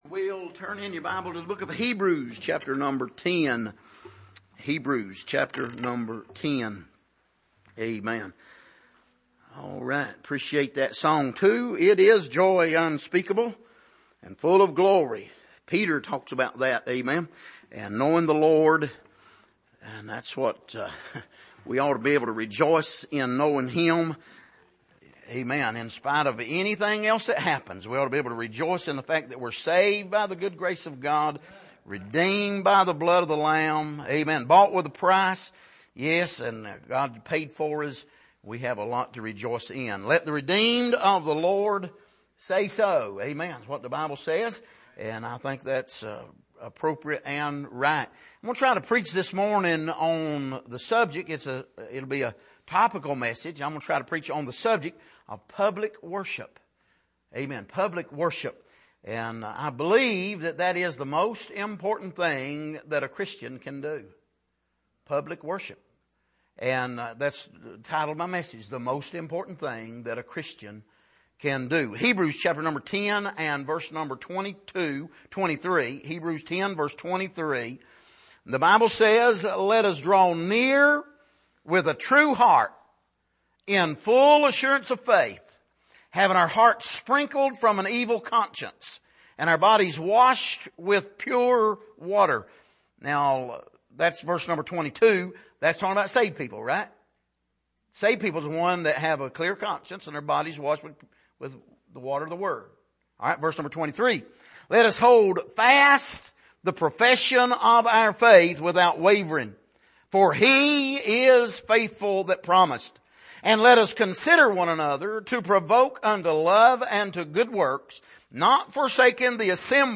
Passage: Hebrews 10:22-25 Service: Sunday Morning